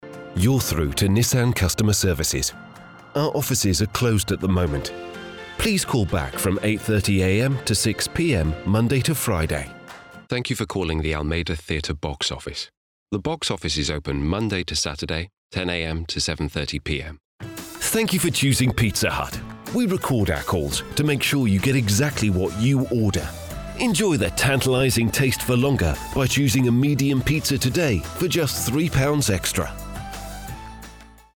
Engels (Brits)
Diep, Natuurlijk, Volwassen, Warm, Zakelijk
Telefonie